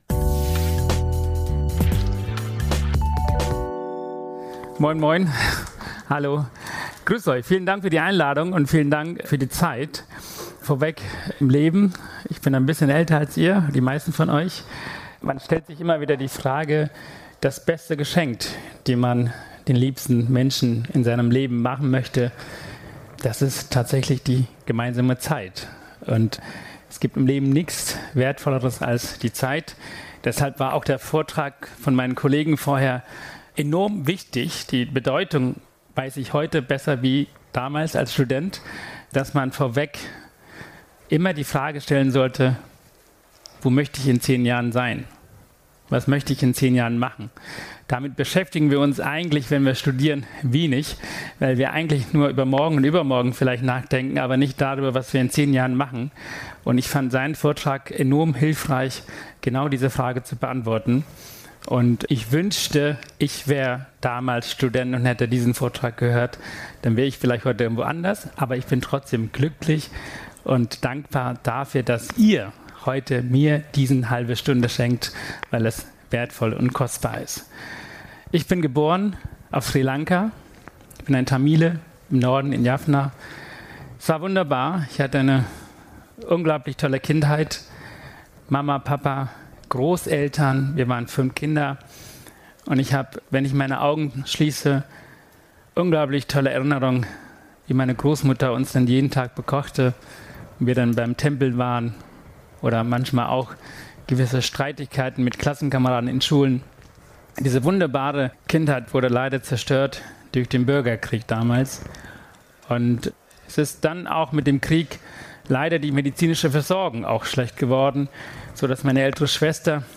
In seinem Vortrag spricht er über seine Erfahrungen.